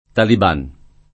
vai all'elenco alfabetico delle voci ingrandisci il carattere 100% rimpicciolisci il carattere stampa invia tramite posta elettronica codividi su Facebook taliban [ talib # n ] o taleban [ taleb # n ] s. m.; pl. -ban o -bani — meno com. il sing. talebano [ taleb # no ]; ma sempre talebano (f. -na ) come aggettivo